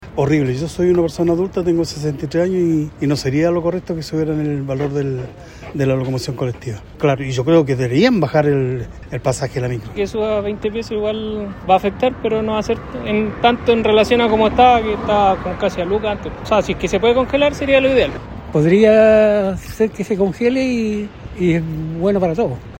La Radio conversó con usuarios del servicio, quienes señalaron que lo ideal sería congelar la tarifa.